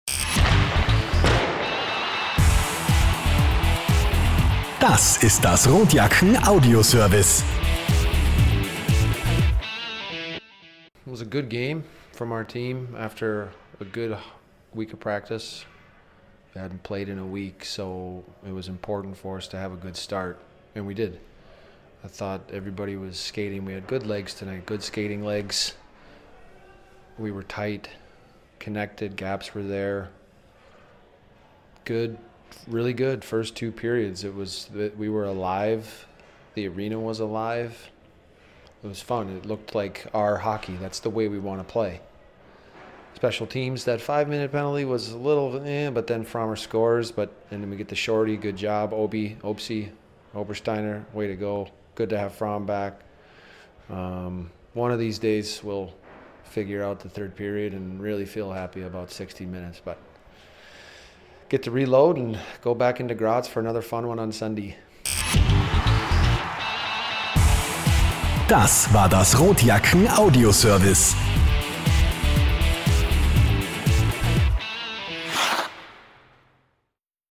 Post Game-Kommentar